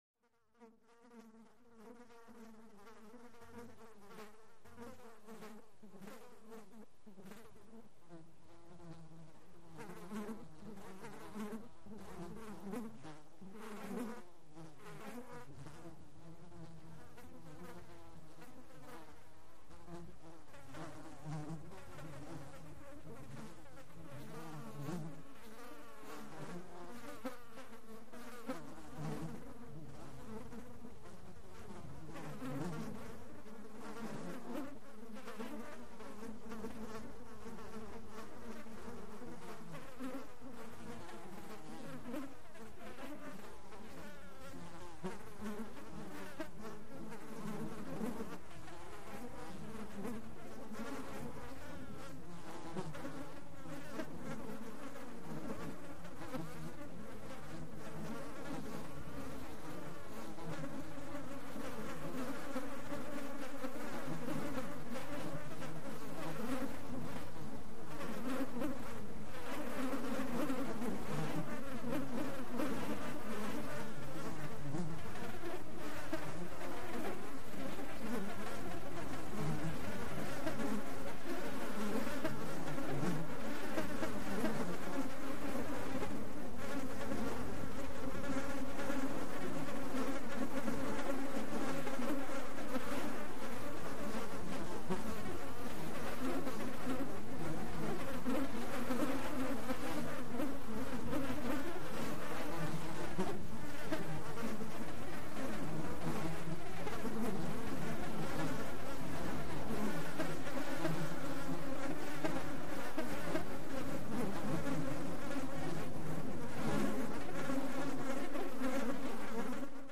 Swarm, Mosquito | Sneak On The Lot
Mosquitoes Buzz And Slowly Grow In Number Into Large Swarm, Heavy Buzz.